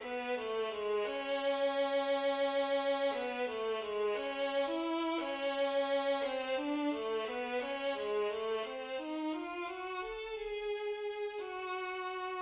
Larghetto 3/4 B major
Excerpt 10 (violin)[n 5]